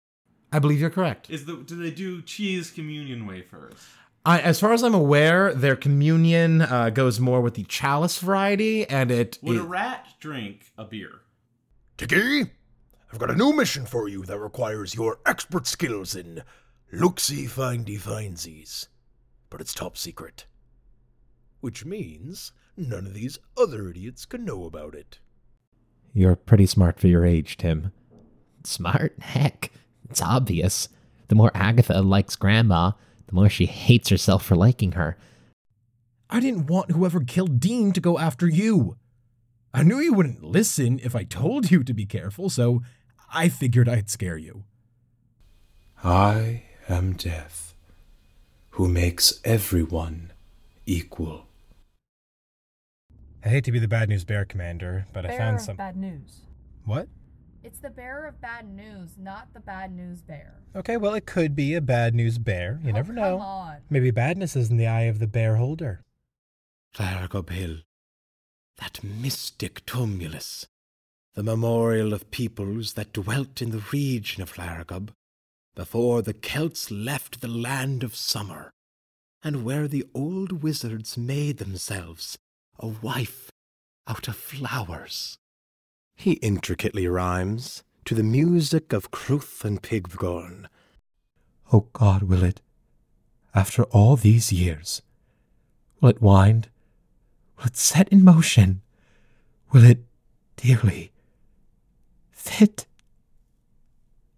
Demo Reel
This reel provides a wide look over many different styles of voiceover I've done. Ranging from podcasts to audio tours to museum displays, this brief track is full of many different samples!